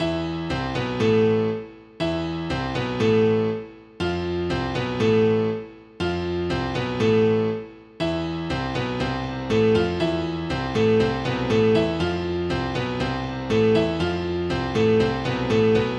标签： 120 bpm Hip Hop Loops Piano Loops 2.69 MB wav Key : Unknown
声道立体声